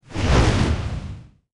fly_on.wav